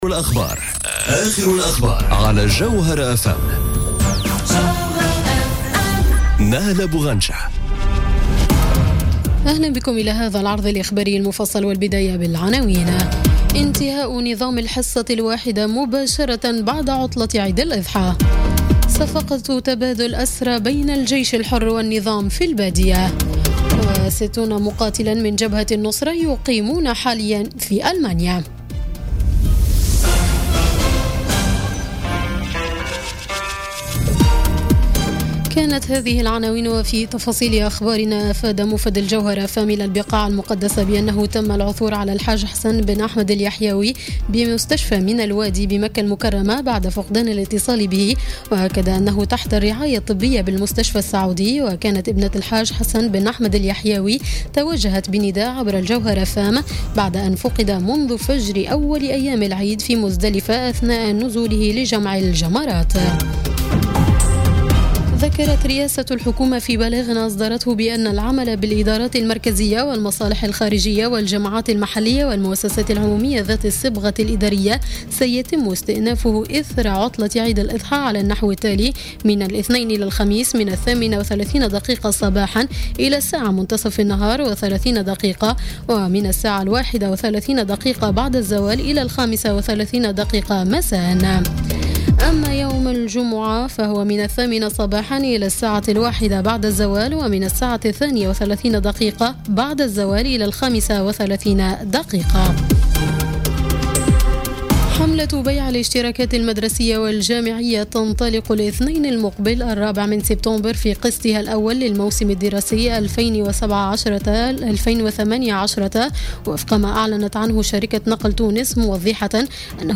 نشرة أخبار السابعة مساء ليوم السبت 2 سبتمبر 2017